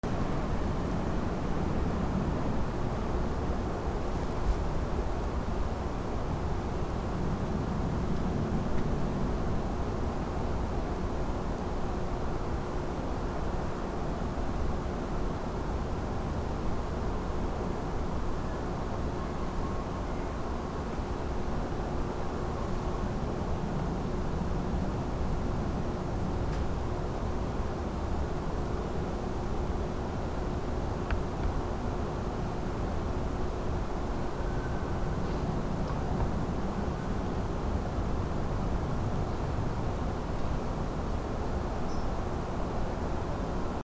/ B｜環境音(自然) / B-45 ｜自然その他
空のうなり
ゴー